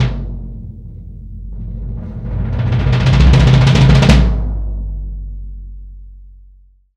Index of /90_sSampleCDs/AKAI S6000 CD-ROM - Volume 3/Kick/GONG_BASS